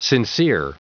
Prononciation du mot sincere en anglais (fichier audio)
Prononciation du mot : sincere